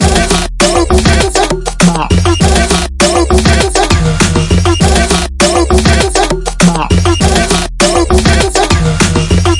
Triphop /舞蹈/拍/嘻哈/毛刺跳/缓拍/寒意
Tag: 寒意 旅行 电子 舞蹈 looppack 样品 毛刺 节奏 节拍 低音 实验 器乐